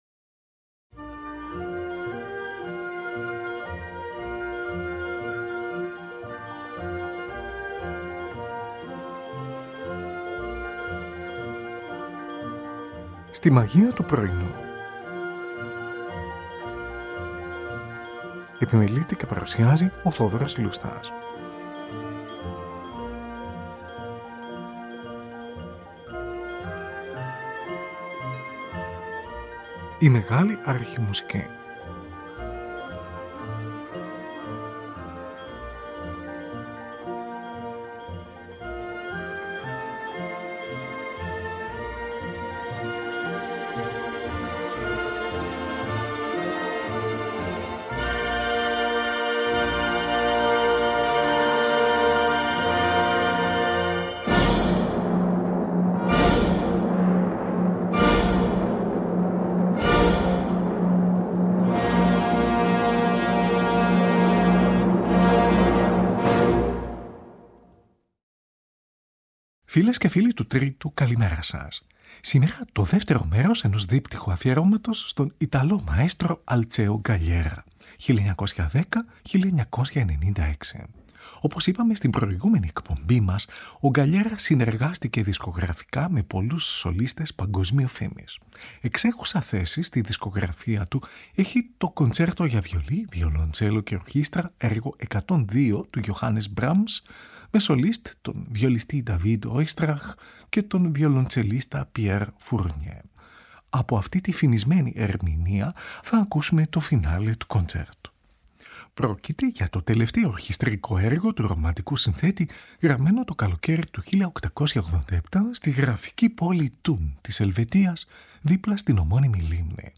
Έξαφνα, η δυναμική και εύθυμη μουσική του πρώτου μέρους, γίνεται σκοτεινή και γαλήνια.
Ακούγονται εκκλησιαστικοί ύμνοι. Μια τρομπέτα παίζει – εκτός σκηνής – τη μελωδία από ένα Sanctus. Χαμηλοί ήχοι εκκλησιαστικού οργάνου και άλλων βαθύφωνων οργάνων εικονογραφούν την υπόγεια κατακόμβη. Παράλληλα, τρομπόνια και κόρνα συμβολίζουν τις ψαλμωδίες ιερέων.